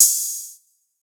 MZ Open Hat [Metro].wav